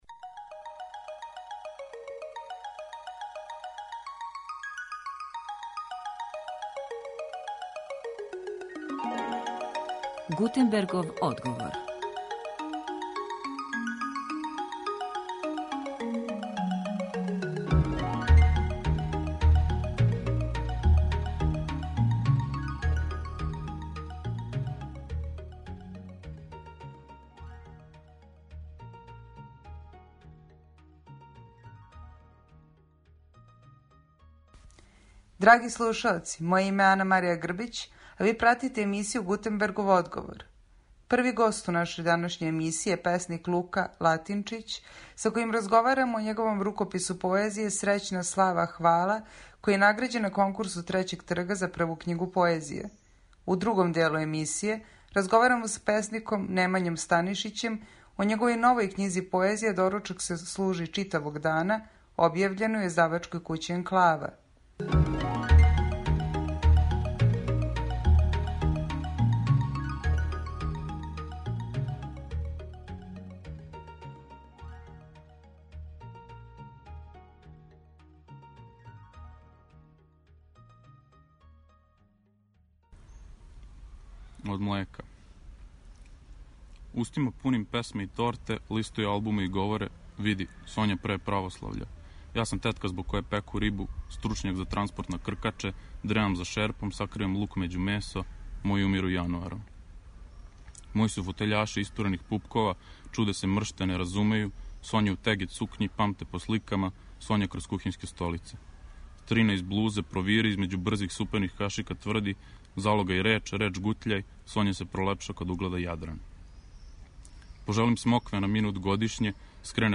Разговарамо о њиховим новим књигама поезије и слушамо поезију коју читају.